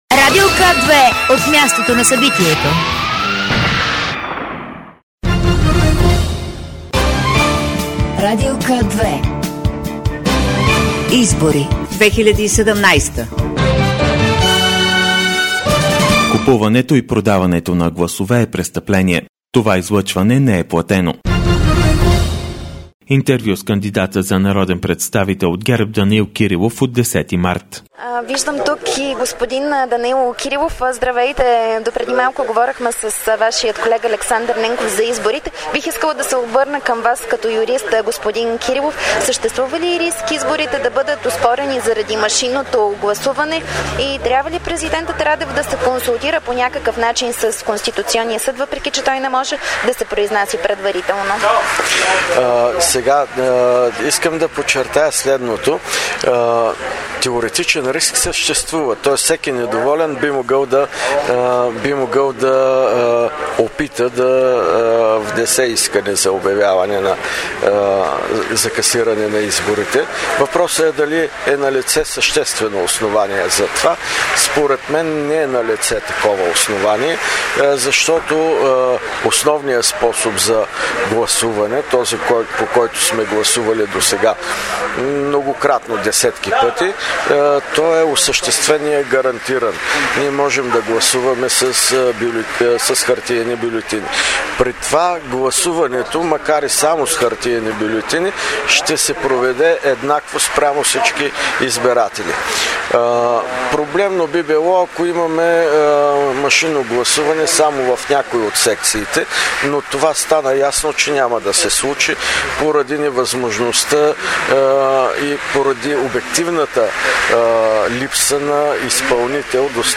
Интервю
- директно от мястото на събитието  (парк - хотел „ Витоша")